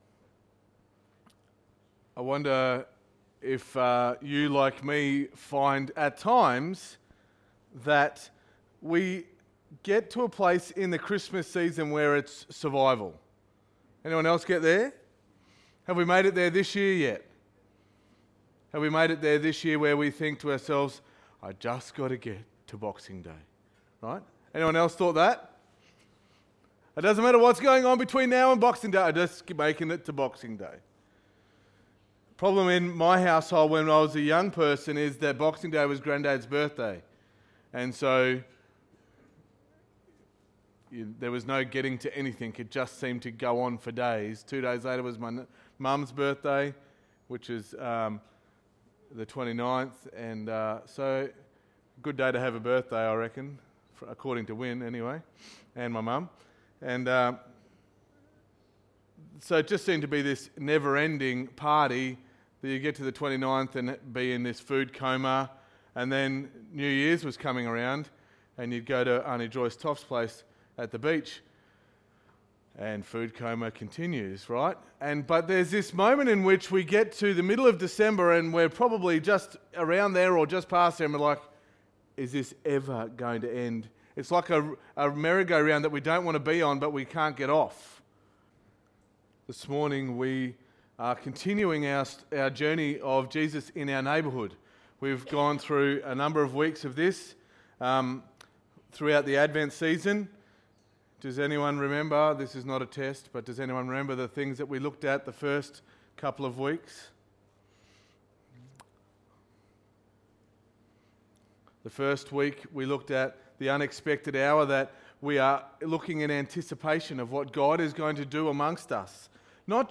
Sermon 22.12.2019